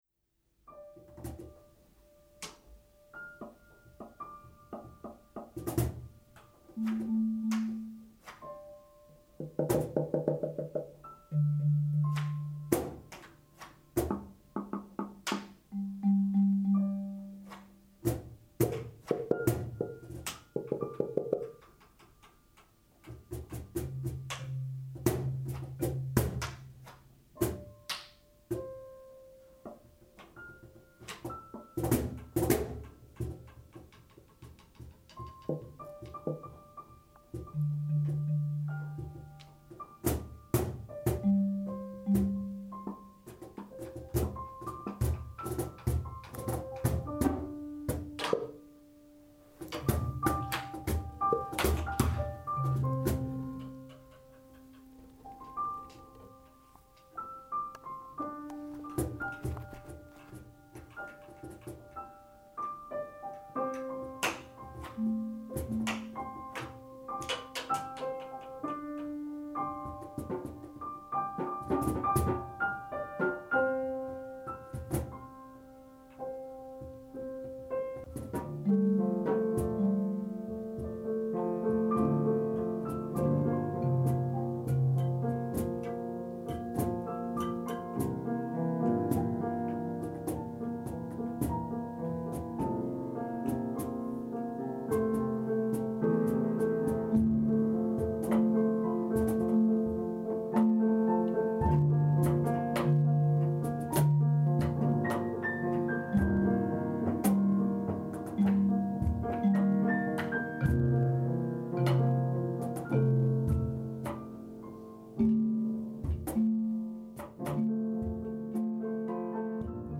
Am 12. Mai präsentieren sieben Teilnehmer des Workshops gemeinsam mit drei Musikern des Gewandhausorchesters die entstandenen drei Stücke der Öffentlichkeit, im Anschluss ans Große Konzert mit der 5. Bruckner-Sinfonie.